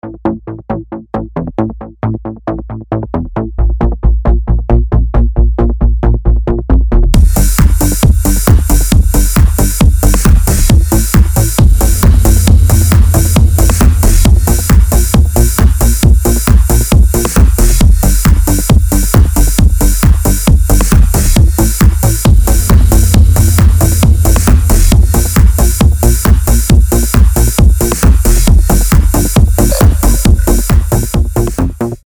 • Качество: 320, Stereo
dance
Electronic
электронная музыка
без слов
Ритмичный рингтон на звонок Rhythmic ringtone on call